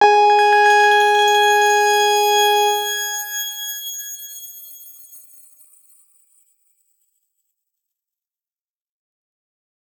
X_Grain-G#4-ff.wav